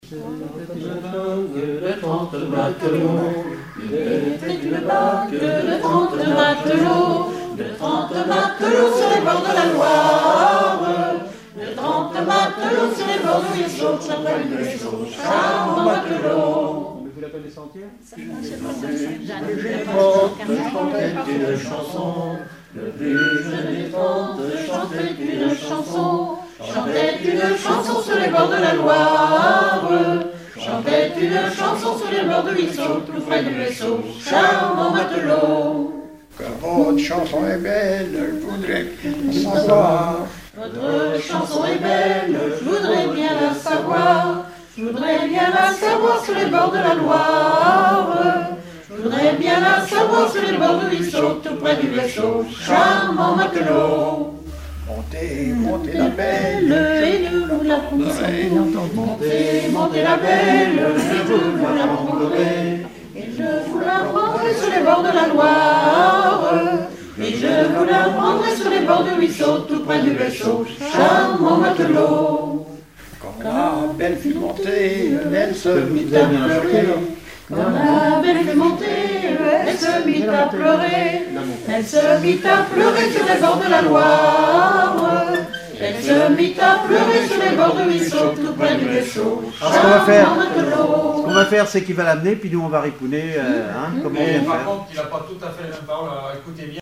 Genre laisse
répertoire de chansons
Pièce musicale inédite